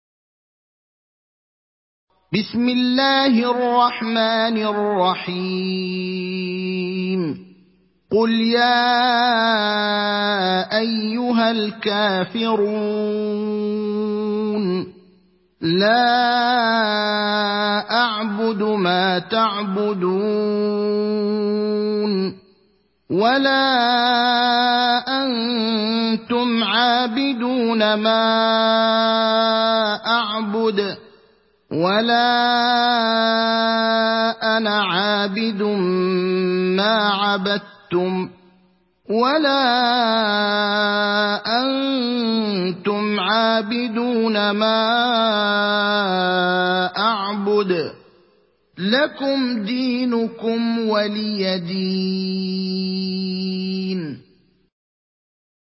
تحميل سورة الكافرون mp3 بصوت إبراهيم الأخضر برواية حفص عن عاصم, تحميل استماع القرآن الكريم على الجوال mp3 كاملا بروابط مباشرة وسريعة